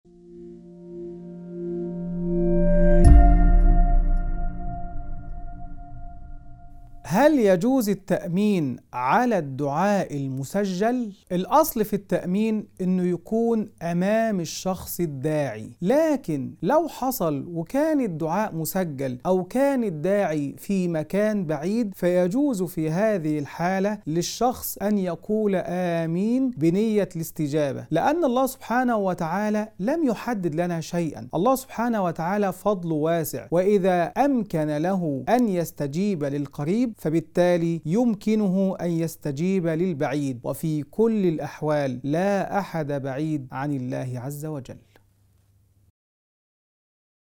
مناقشة شرعية حول حكم التأمين على الدعاء المسجل، مع بيان أن استجابة الله تعالى لا ترتبط بمسافة الداعي أو طريقة الدعاء، بل برحمة الله الواسعة وإحاطته بكل شيء.